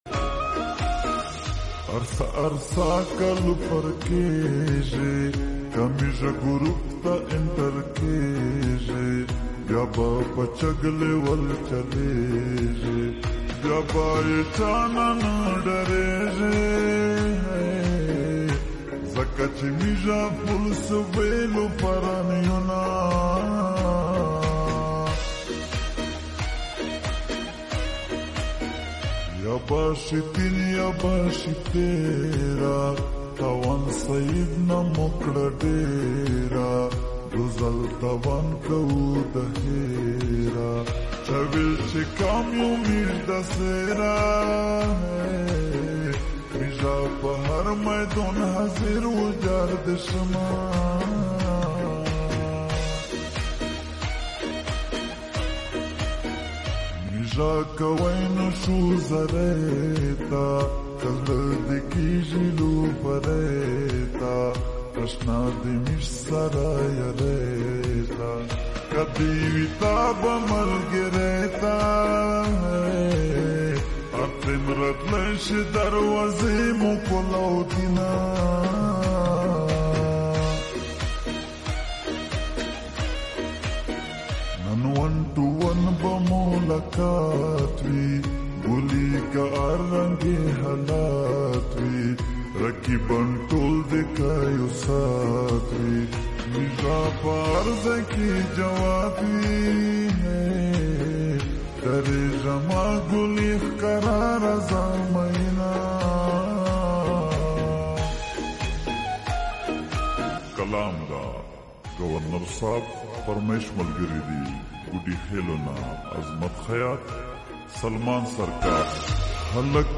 Pashto Lofar Song
Pashto Mast Song